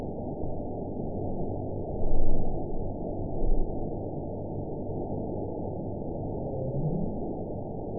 event 917665 date 04/11/23 time 21:17:06 GMT (2 years, 1 month ago) score 9.04 location TSS-AB03 detected by nrw target species NRW annotations +NRW Spectrogram: Frequency (kHz) vs. Time (s) audio not available .wav